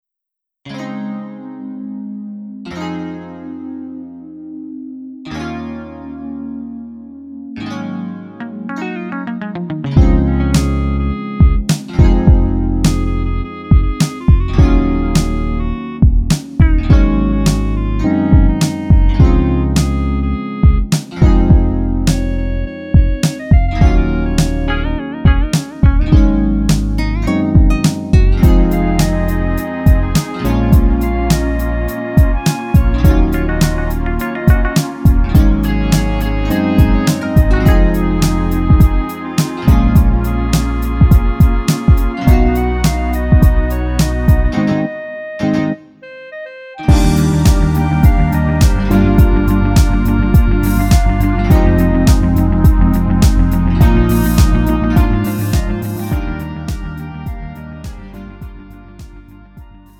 음정 -1키 3:41
장르 구분 Lite MR